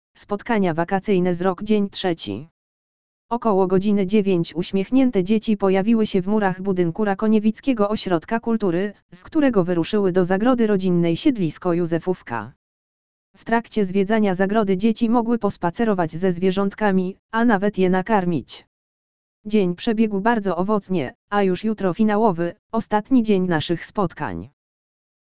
lektor_audio_spotkania_wakacyjne_z_rok_dzien_trzeci.mp3